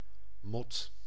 Ääntäminen
Synonyymit ruzie geschil strijd herrie nachtvlinder onmin tweespalt stomp Ääntäminen : IPA: [mɔt] Haettu sana löytyi näillä lähdekielillä: hollanti Käännös 1. polilla {f} Suku: c .